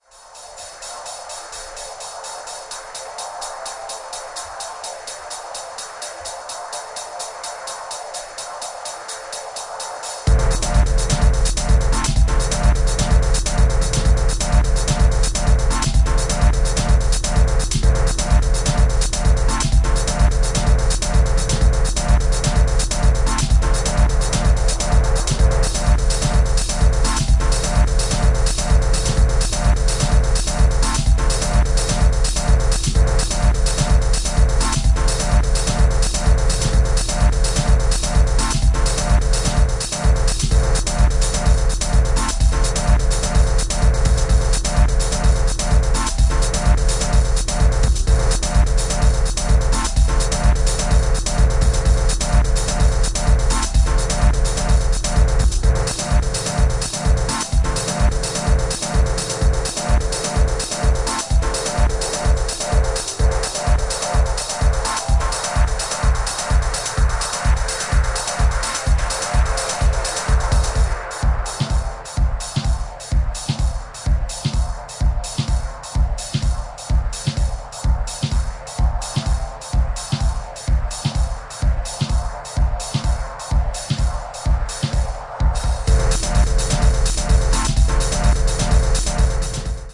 TECHNO/ELECTRO